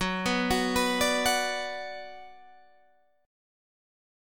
Gbsus4#5 Chord